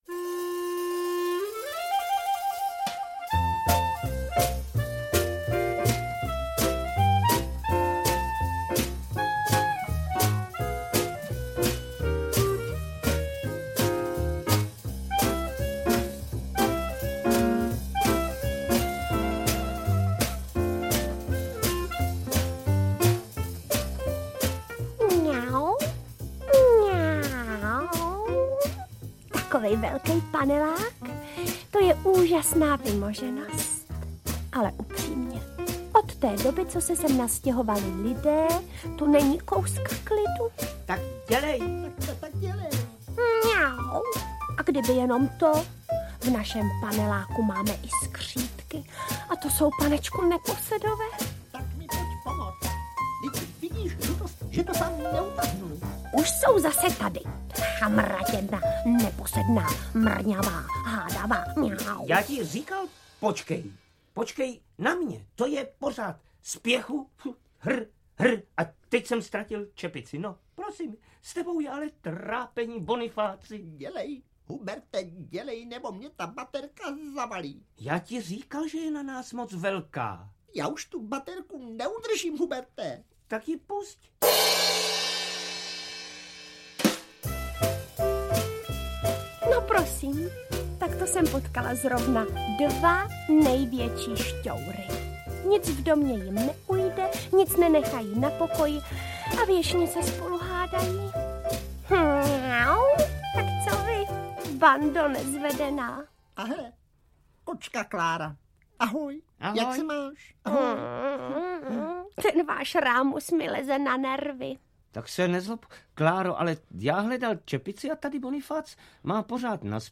- Jan Kramařík, Jaroslav Nečas - Audiokniha